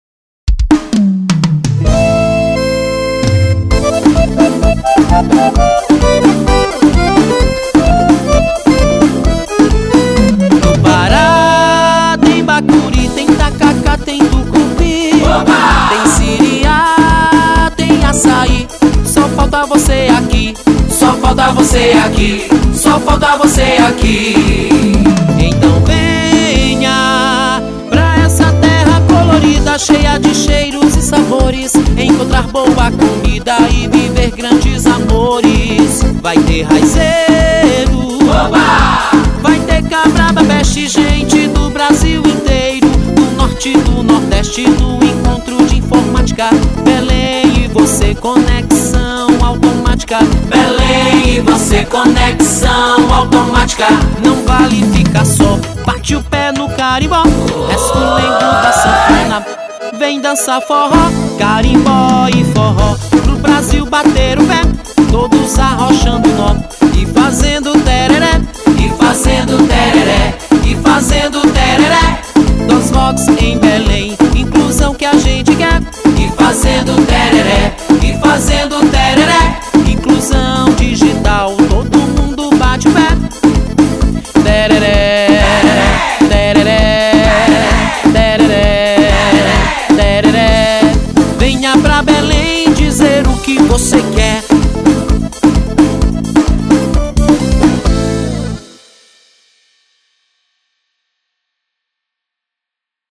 Música Tema
(Mensagem sonora do Dosvox)